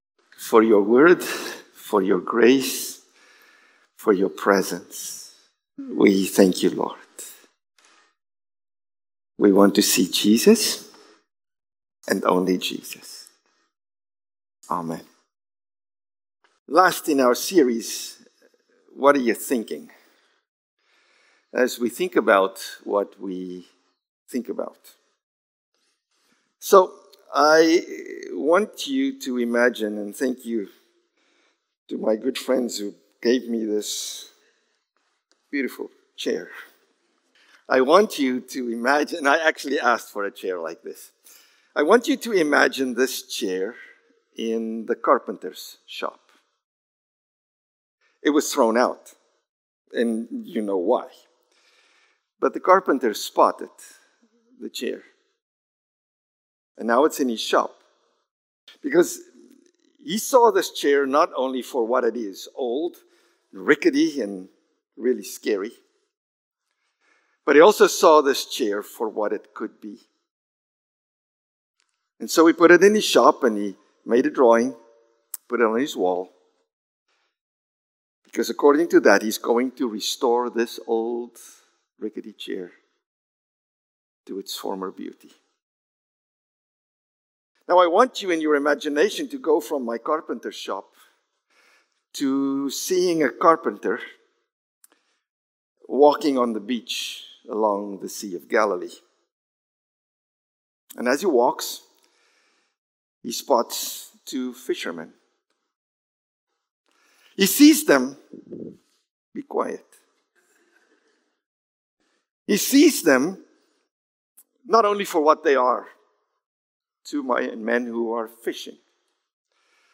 June-29-Sermon.mp3